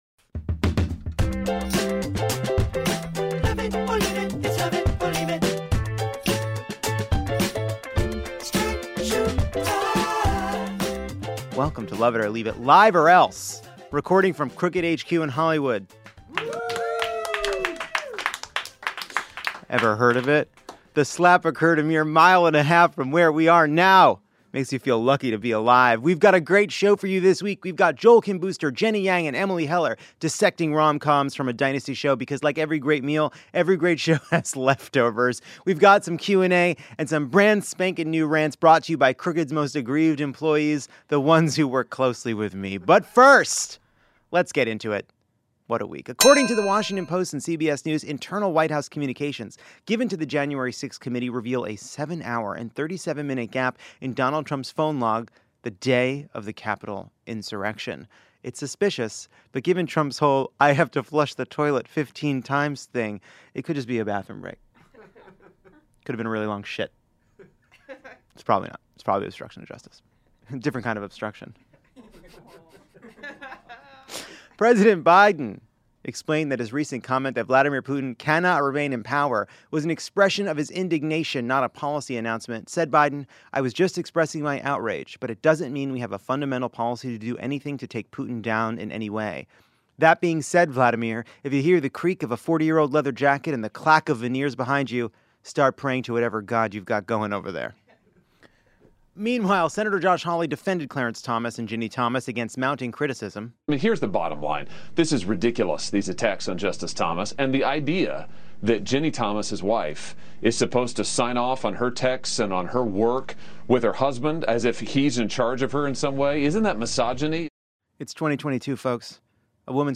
Joel Kim Booster, Jenny Yang and Emily Heller talk rom-coms in a segment slow-cooked to perfection from a recent Dynasty Typewriter show. Lovett fills up on audience questions, while Crooked’s own in-house cranks rant about in-flight films, late-night anxiety, and the eternal dessert rivalry between chips and nuts.